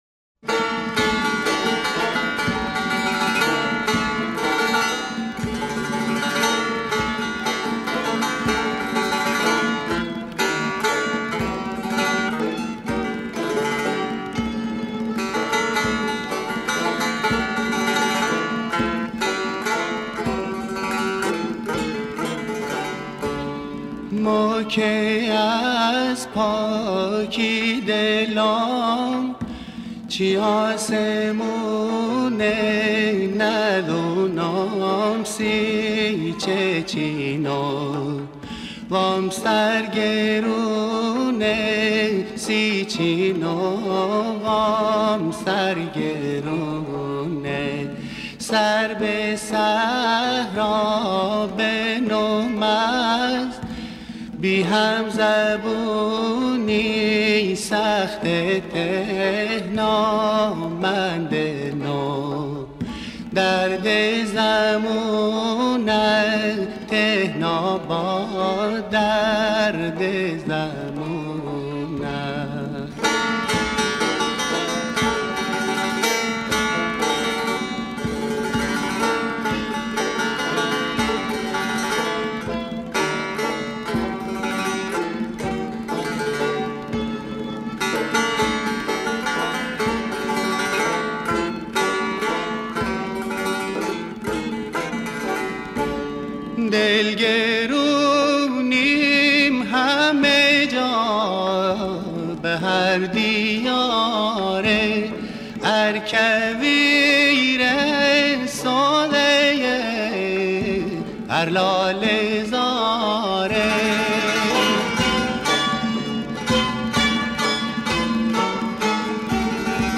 سه تار